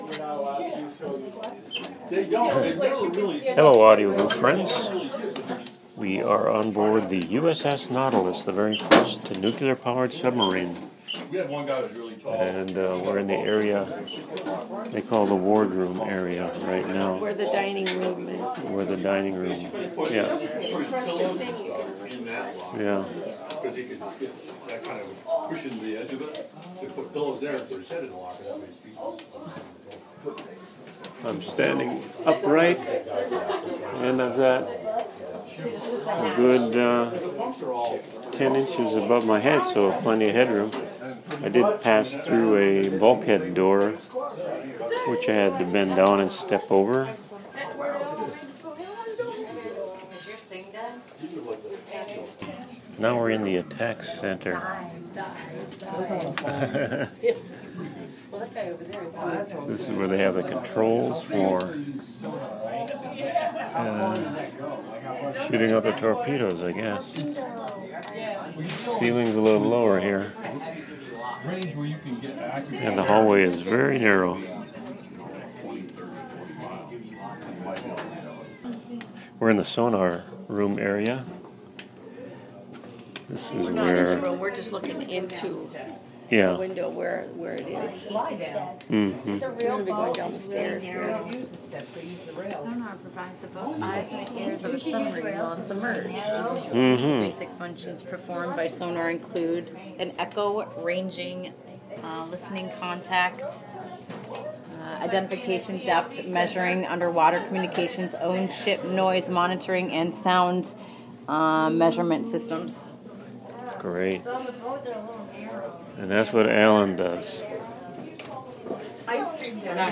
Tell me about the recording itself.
Aboard the first nuclear submarine, the U.S.S. Nautilus.